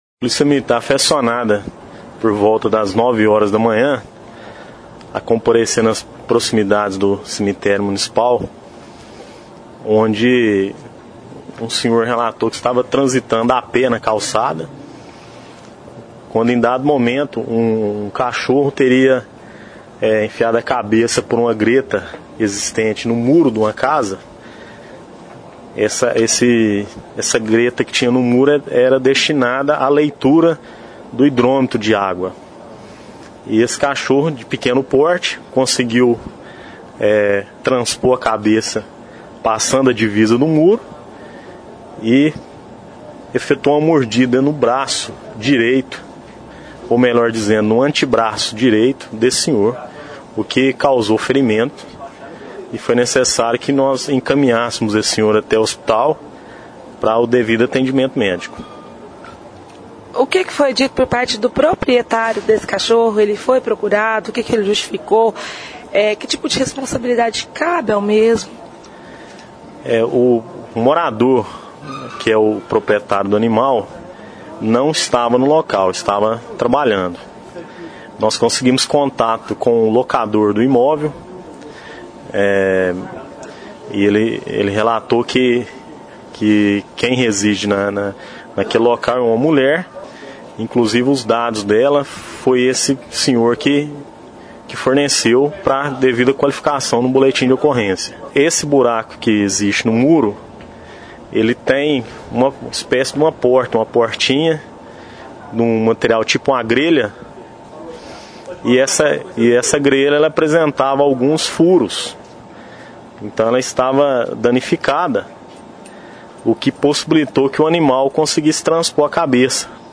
Um aposentado foi atacado ontem (07/04) por um cão nas proximidades do cemitério municipal. (Clique no player e ouça a entrevista).